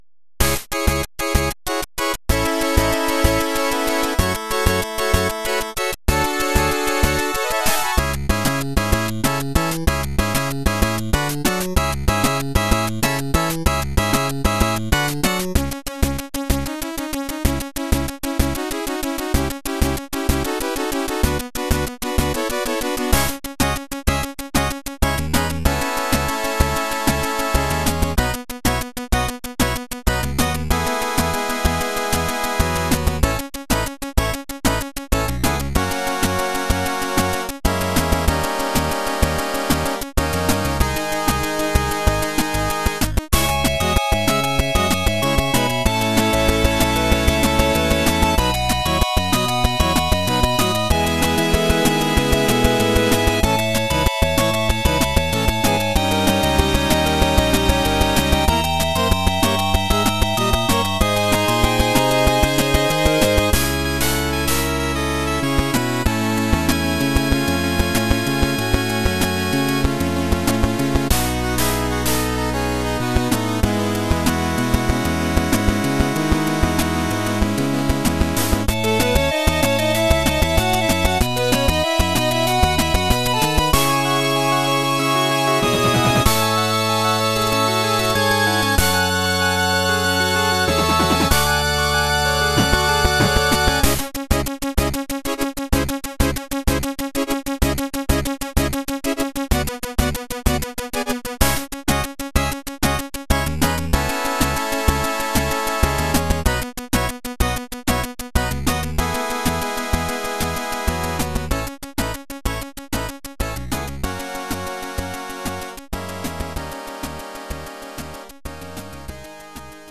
何がすごいって, MIDIデータをPSGで再生できるんですよ。
PSGの味わいはわかる人だけわかってください。